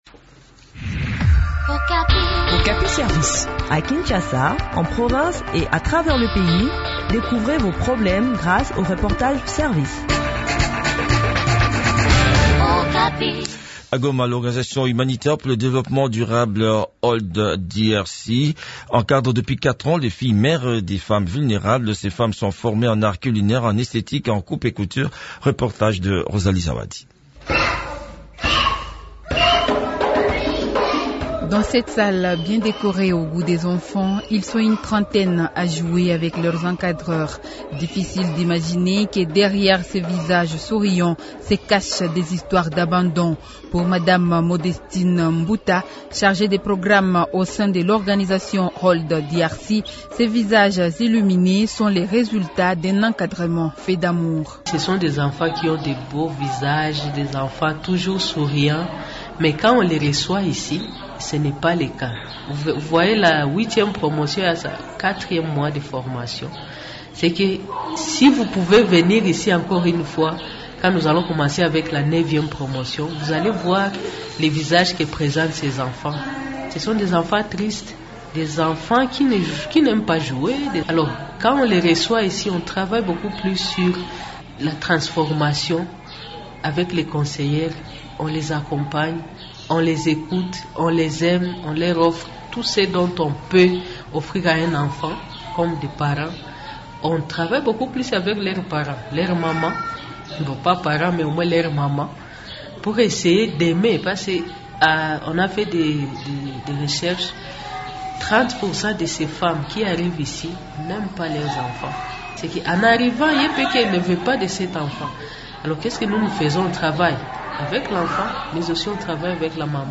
Le point sur l’exécution de ce programme dans cet entretien